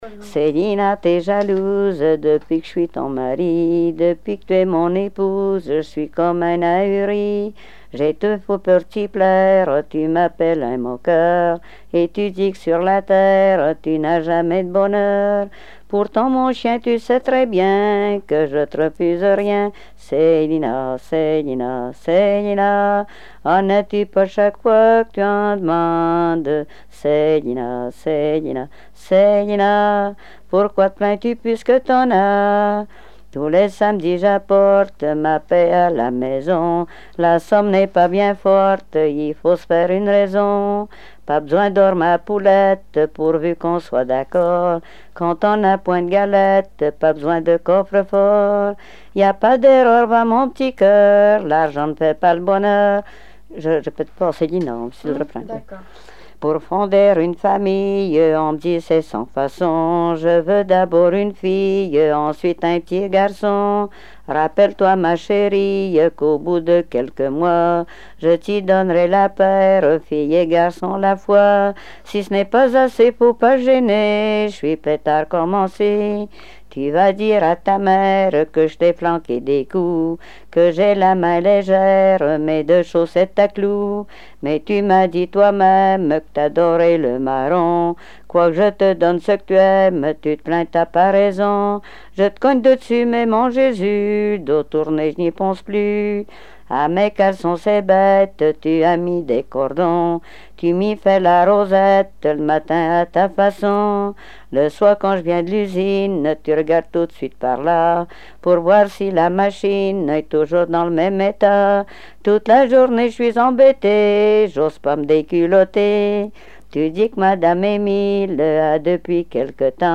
Genre strophique
Répertoire de chansons traditionnelles et populaires
Pièce musicale inédite